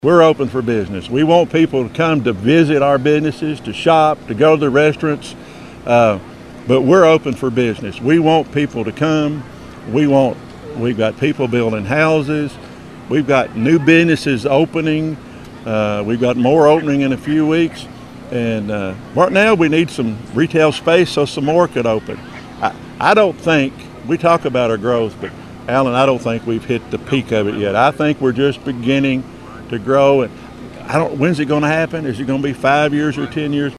Mayor Green told WEKT News during the recent Todd County Harvest Festival there is an exciting forward momentum in Elkton and Todd County.